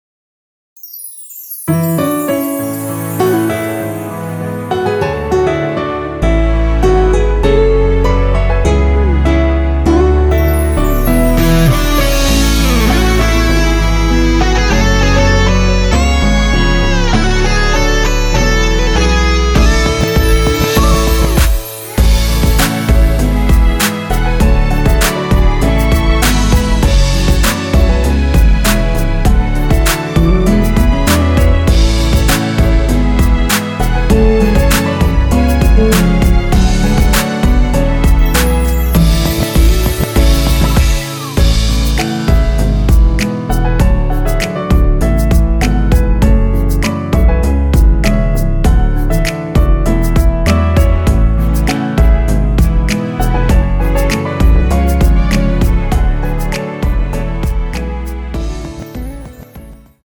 음정은 반음정씩 변하게 되며 노래방도 마찬가지로 반음정씩 변하게 됩니다.
앞부분30초, 뒷부분30초씩 편집해서 올려 드리고 있습니다.
중간에 음이 끈어지고 다시 나오는 이유는